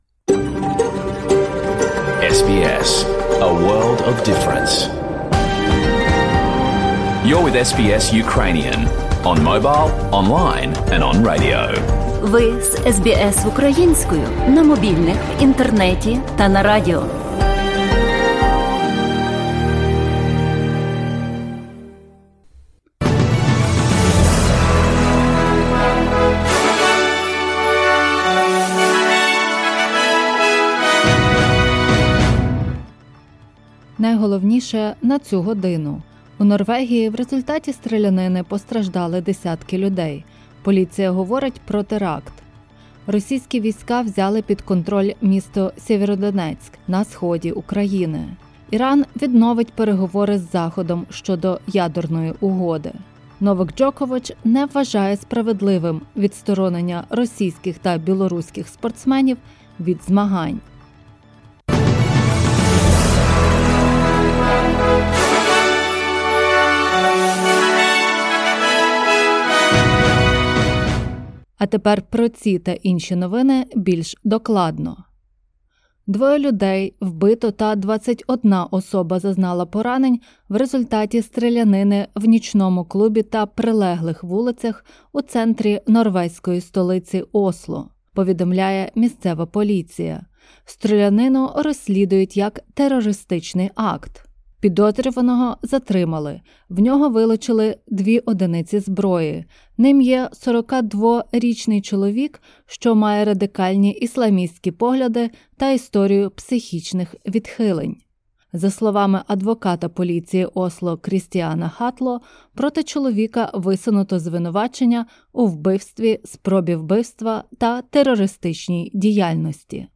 In this bulletin: Norway raises its terrorism threat after a deadly shooting in the capital Oslo.